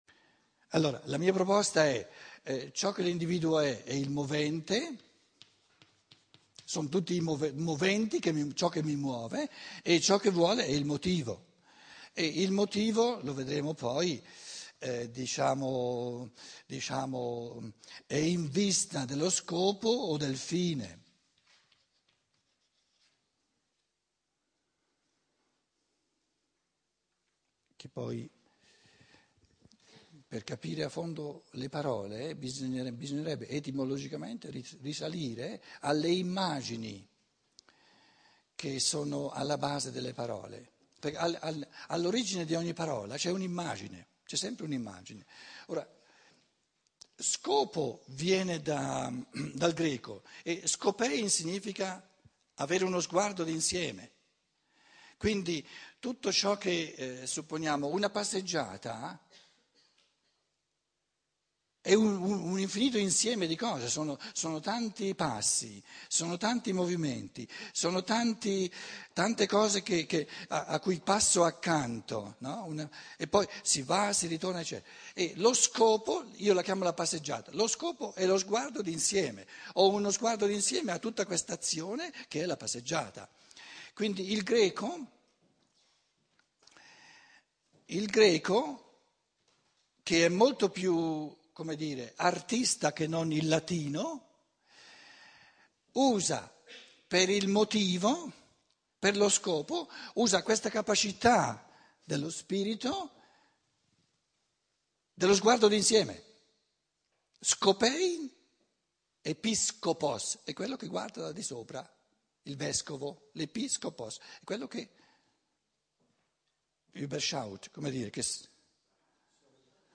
06B - Sesta conferenza - sabato pomeriggio